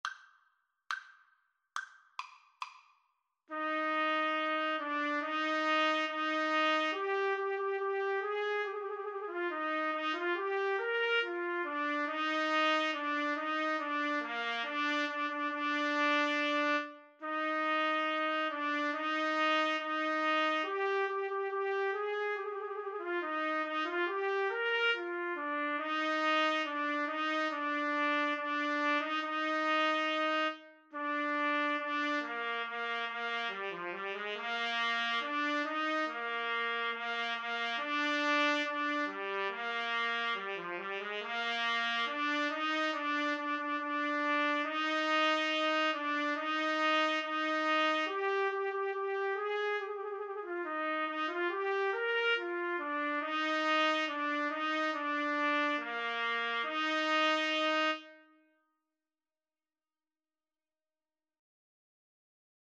Allegro Maestoso = 70 (View more music marked Allegro)
Trumpet Duet  (View more Easy Trumpet Duet Music)